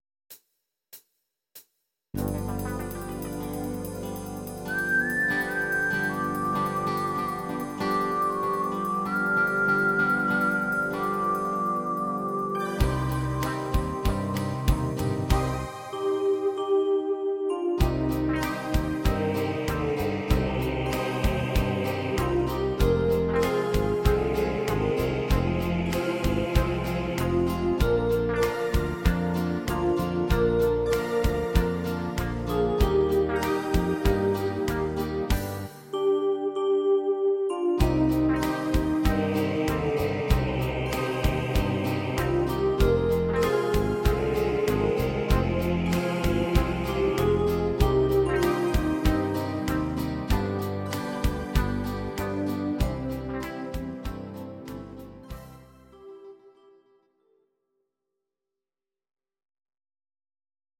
Audio Recordings based on Midi-files
German, Traditional/Folk, Volkstï¿½mlich